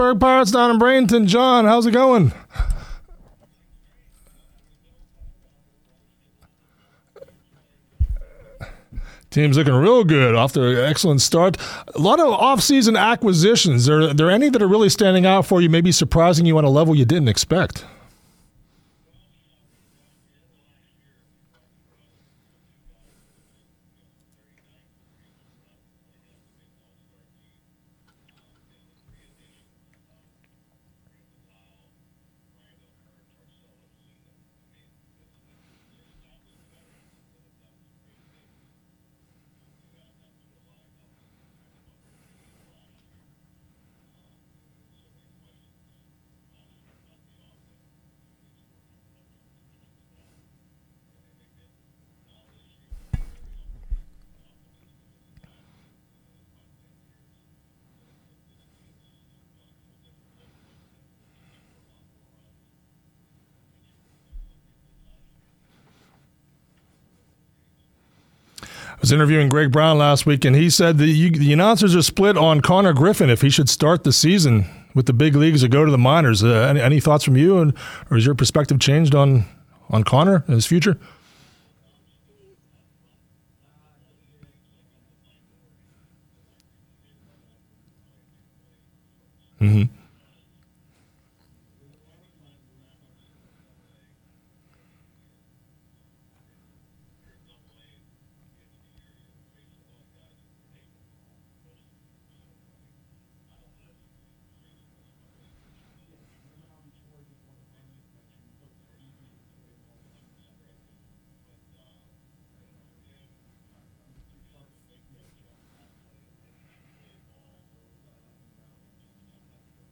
(The Pittsburgh Pirates broadcasting team will be guests on the Beaver County Radio Morning Show each Monday, Wednesday & Friday, phoning from Bradenton with a Spring Training report.)